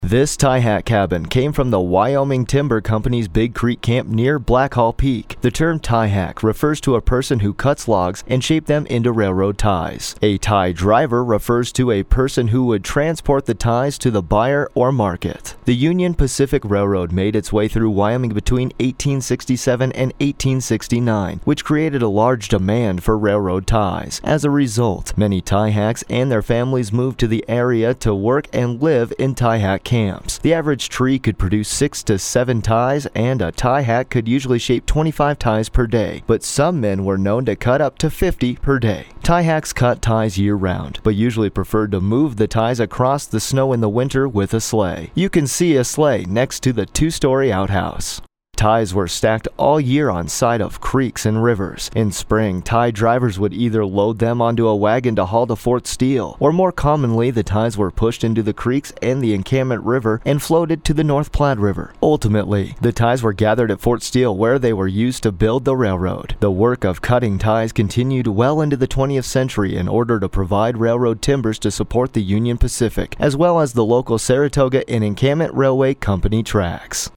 Audio Tour